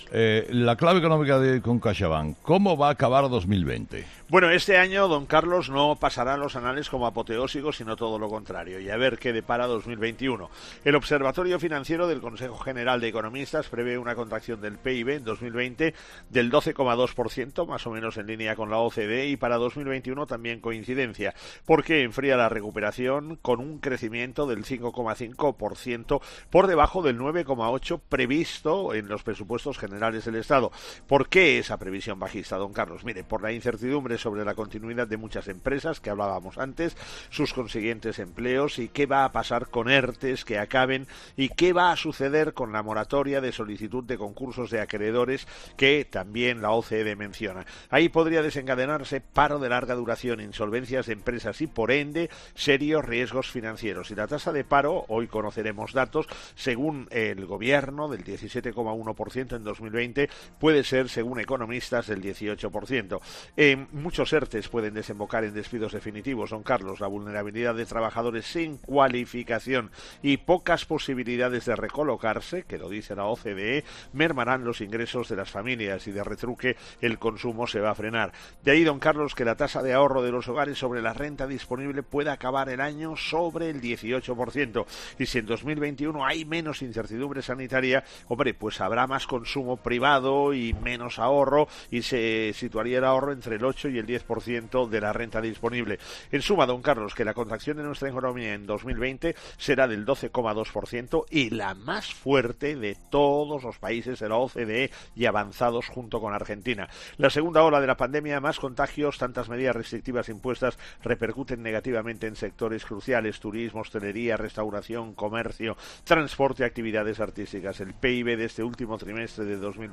El profesor José María Gay de Liébana analiza en'Herrera en COPE’ las claves económicas del día.